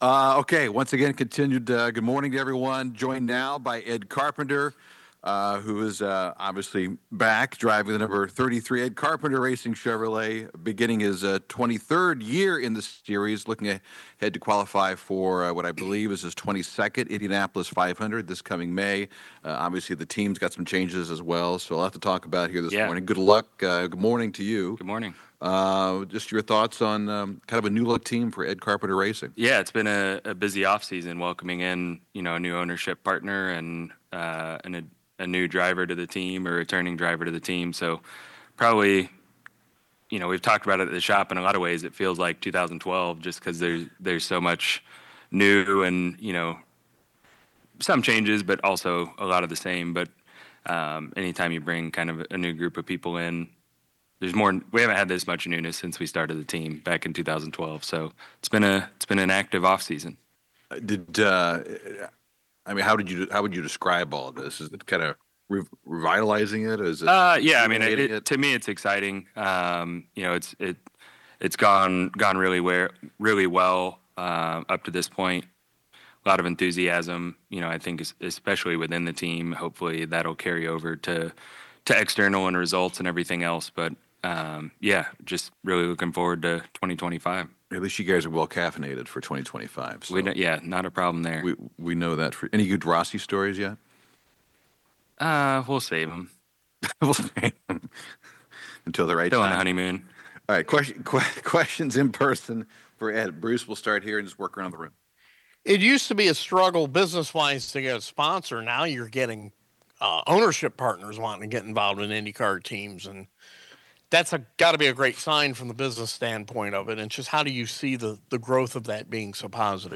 Ed Carpenter IndyCar on January 2025 Zoom with Media